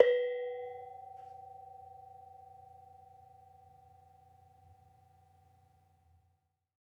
Bonang-B3-f.wav